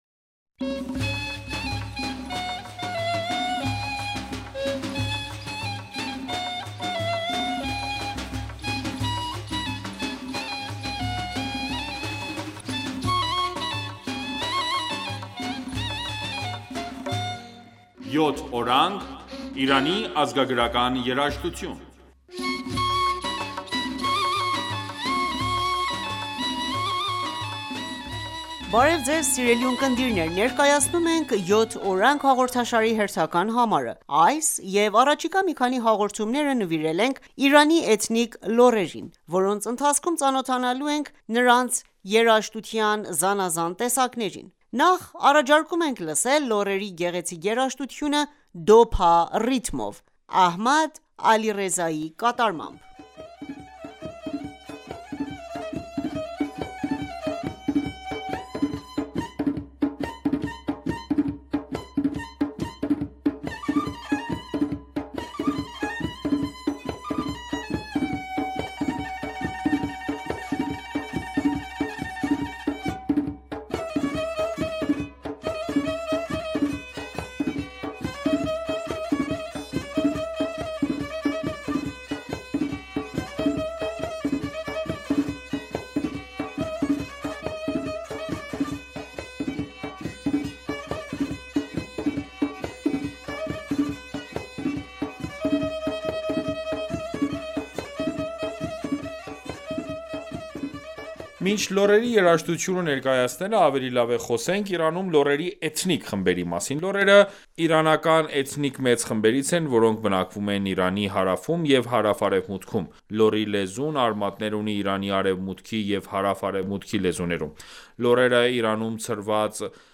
Բարև Ձեզ սիրելի ունկնդիրներ , ներկայացնում եմ «Յոթ Օրանգ»հաղորդաշարի հերթական համարը:Այս և առաջիկա մի քանի հաղորդումները նվիրել ենք Իրանի էթնիկ լոռերին,...